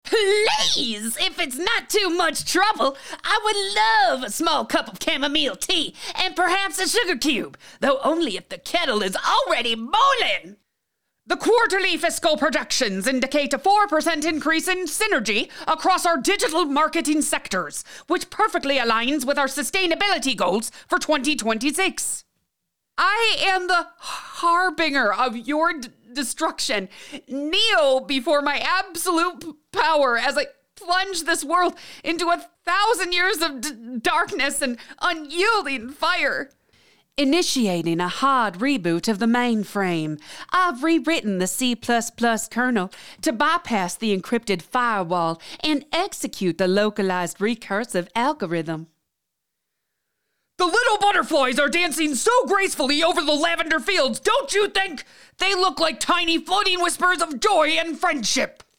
Character Voices Demo
British, Irish, Southern, Australian